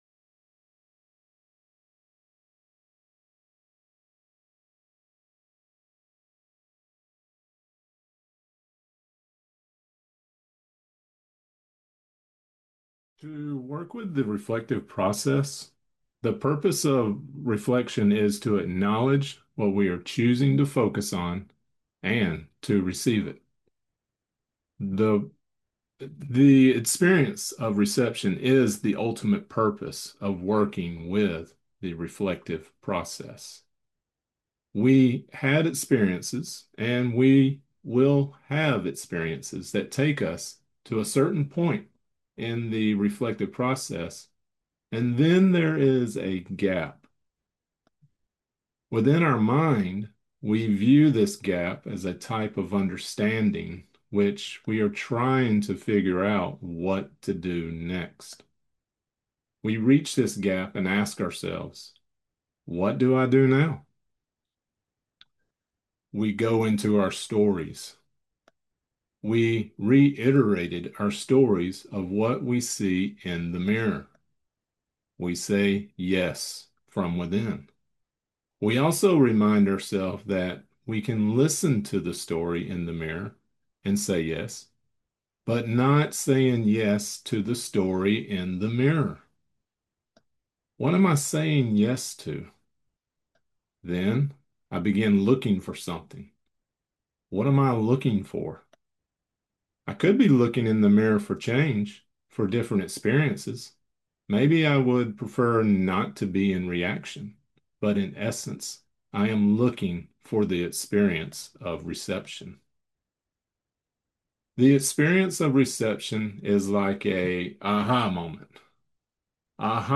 This lecture includes the channeling of Vywamus working with me on identifying and clearing non-receptive patterns within my subconsciousness and cellular memory. Non-receptive qualities can be identified with our judgments, denial, the belief of lack, conditions, guilt, and stories we have created for ourselves in this lifetime.